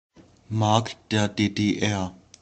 The East German mark (German: Mark der DDR [ˈmaʁk deːɐ̯ ˌdeːdeːˈʔɛʁ]